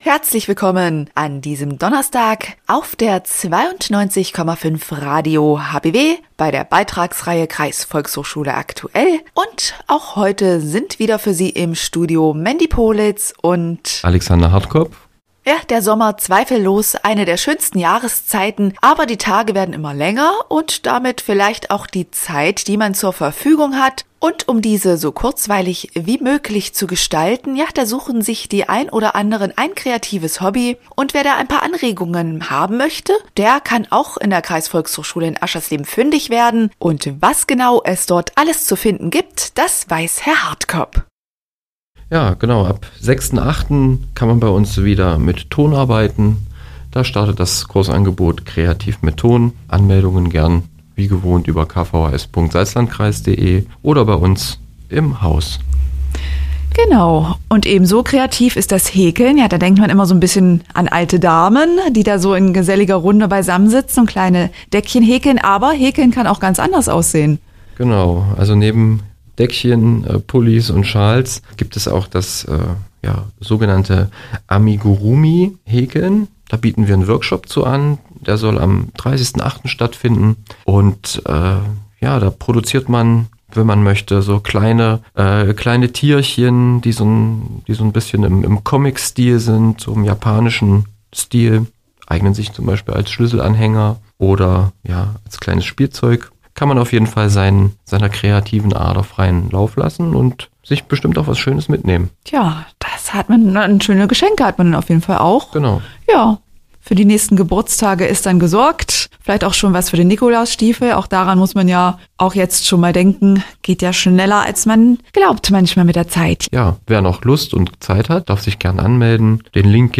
Hörbeitrag vom 20. Juni 2024